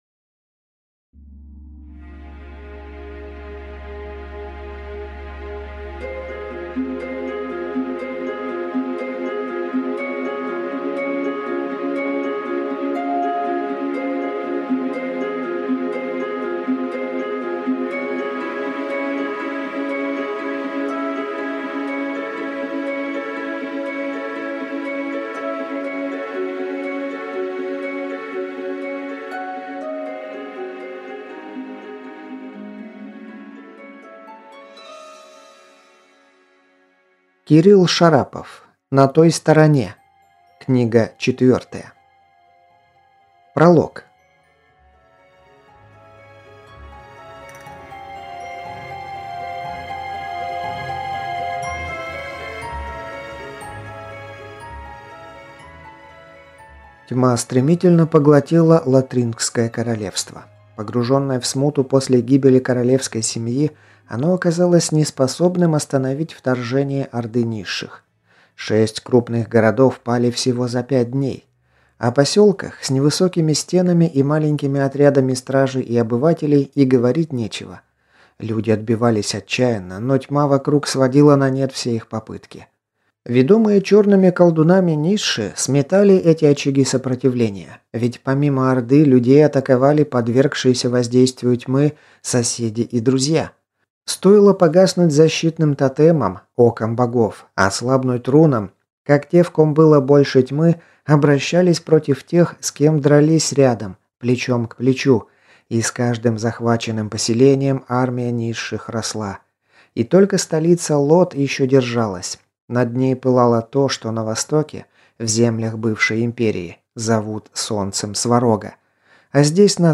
Аудиокнига На той стороне – 4. Темные времена | Библиотека аудиокниг